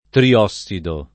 [ tri- 0SS ido ]